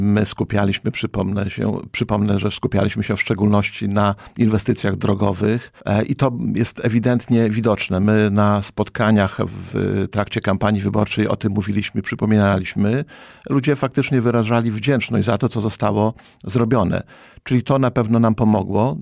Starosta łomżyński mówił na naszej antenie, że tak dobry wynik, to dobra ocena pracy, którą wykonał zarząd powiatu w ostatnich 5 latach.